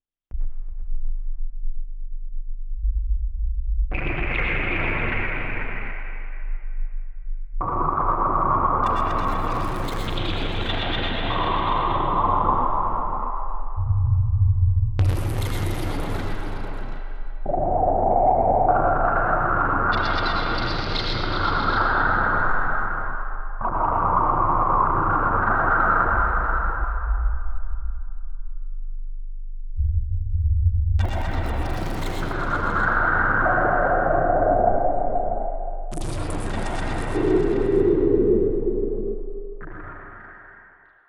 Nightmare_ambience_3.wav